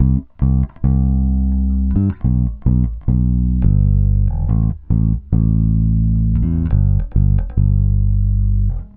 Retro Funkish Bass 02a.wav